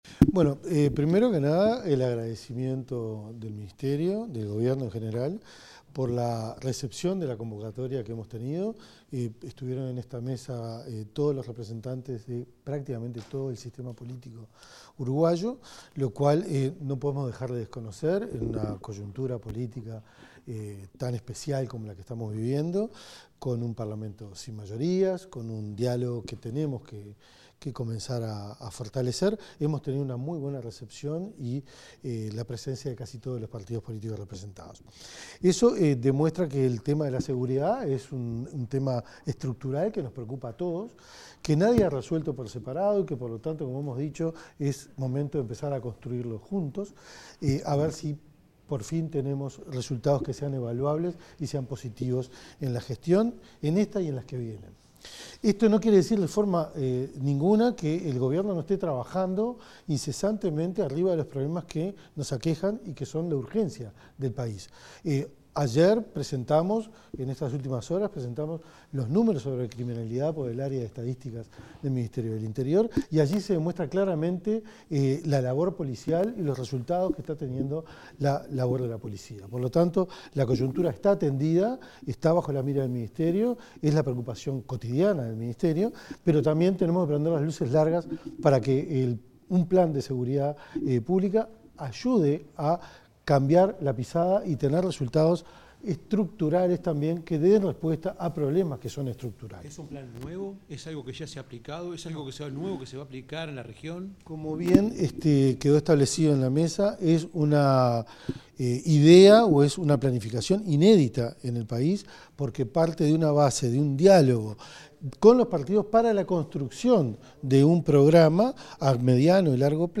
Declaraciones del ministro del Interior, Carlos Negro
Declaraciones del ministro del Interior, Carlos Negro 23/07/2025 Compartir Facebook X Copiar enlace WhatsApp LinkedIn Tras un encuentro con representantes de los partidos políticos para abordar el Plan Nacional de Seguridad Pública, el ministro del Interior, Carlos Negro, dialogó con la prensa.